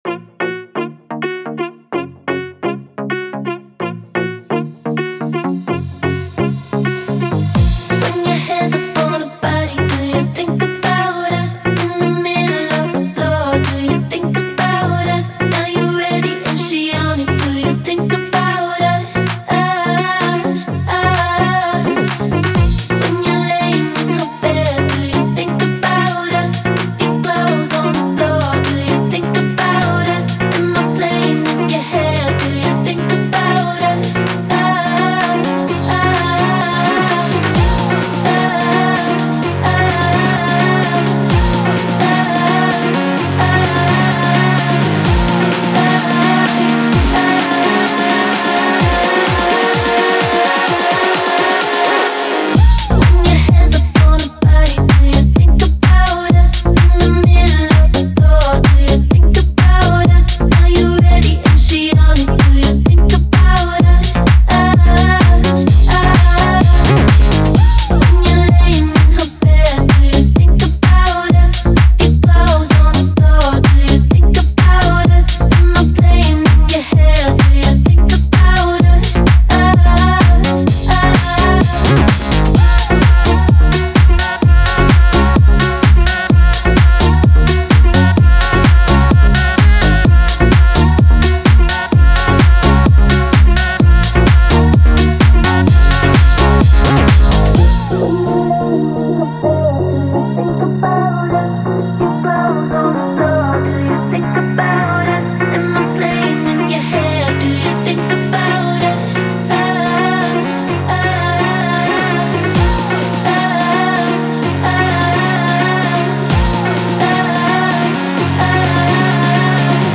BPM128-128
Audio QualityPerfect (High Quality)
House song for StepMania, ITGmania, Project Outfox
Full Length Song (not arcade length cut)